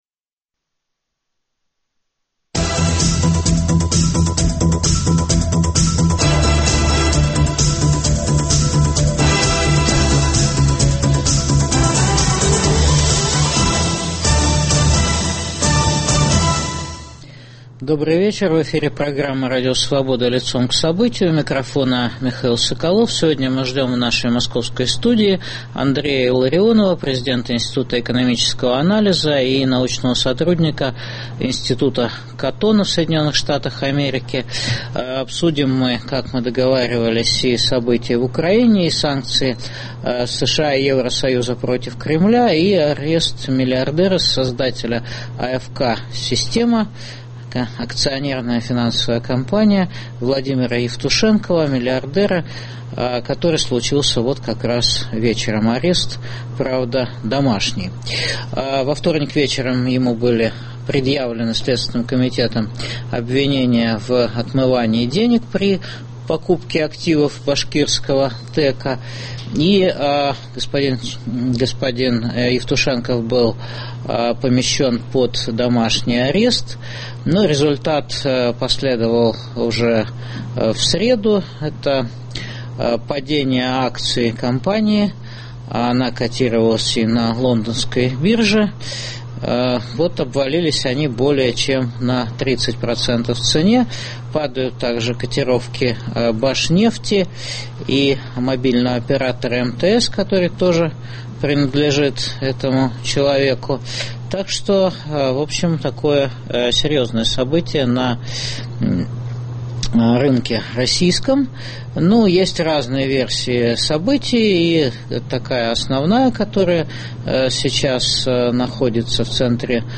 С научным сотрудником Института Катона, президентом Института экономического анализа Андреем Илларионовым в прямом эфире обсуждаем агрессию режима Путина в Украине, санкции США и Евросоюза против Кремля и арест миллиардера создателя АФК "Система" Владимира Евтушенкова.